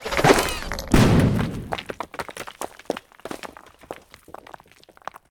grenade.ogg